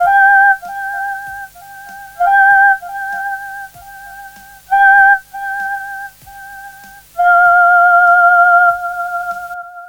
FK097SYNT1-R.wav